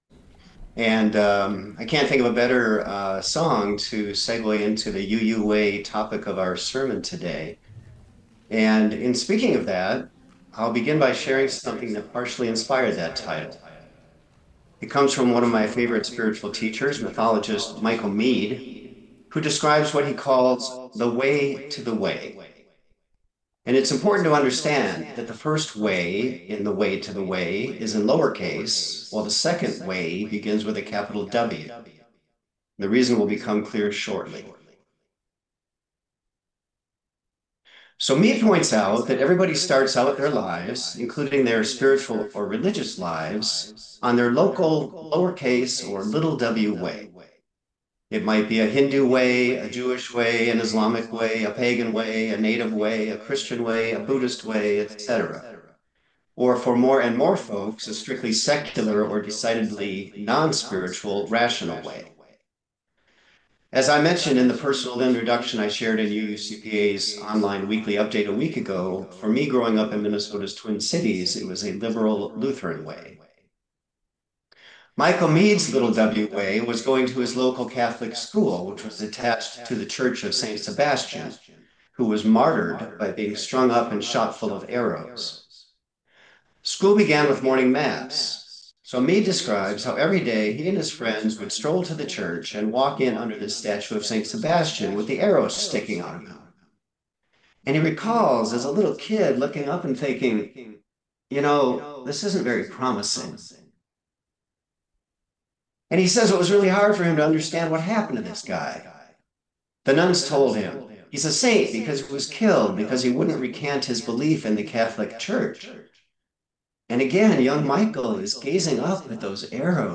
Sermon audio can be found on each service's page (select the service title below), followed by a video of the full service if available (starting April 12, 2020